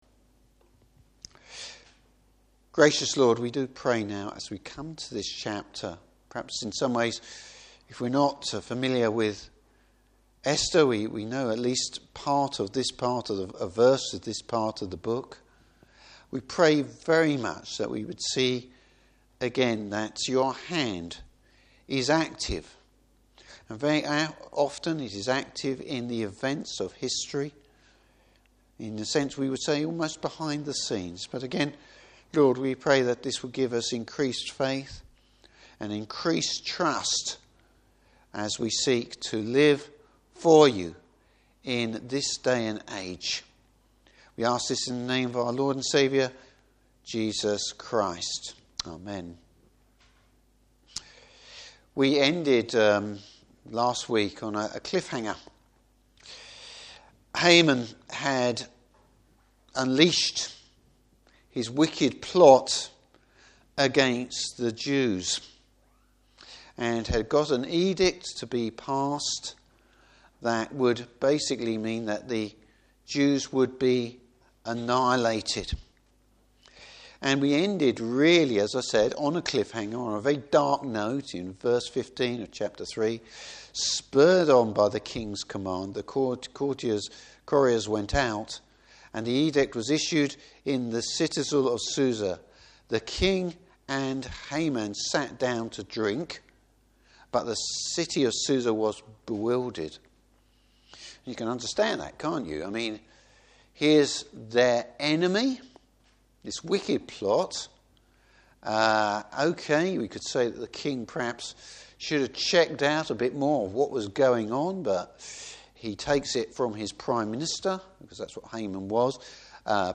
Service Type: Evening Service Time for Esther to make a stand.